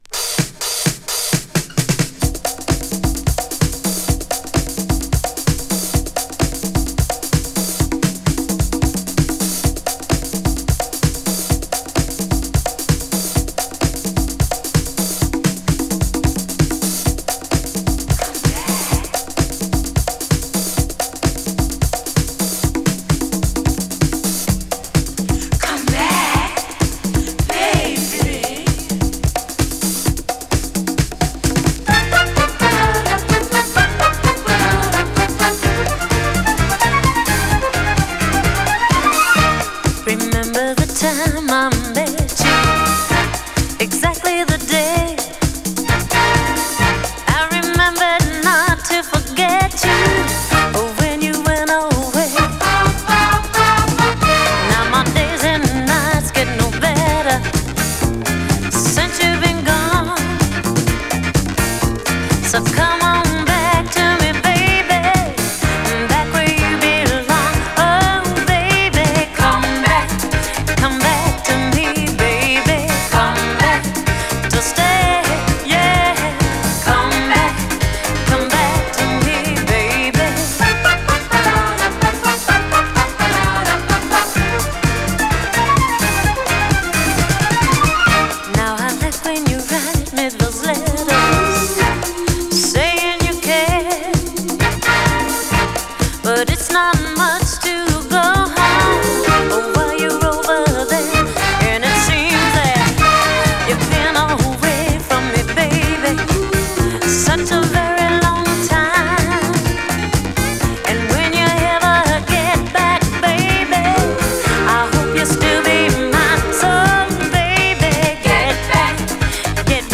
オリジナル・マスターテープからのリマスター音源を収録。